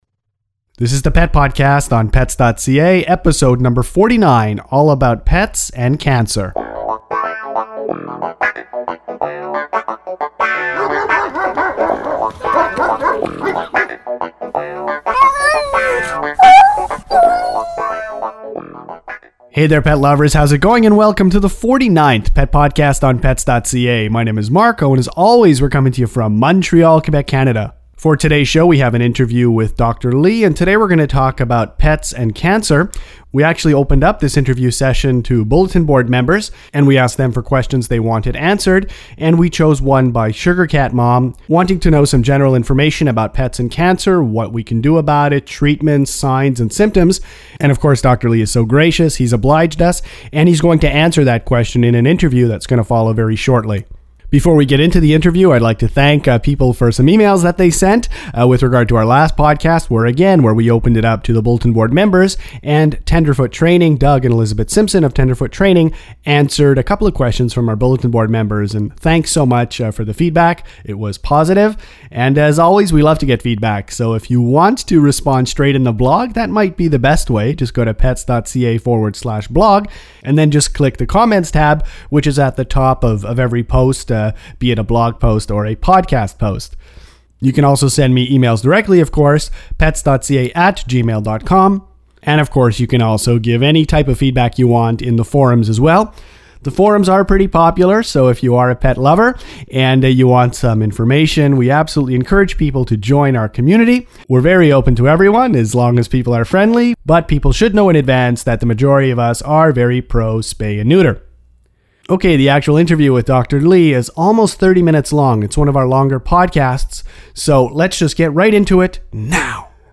49 – Dogs – cats – pets and cancer – Interview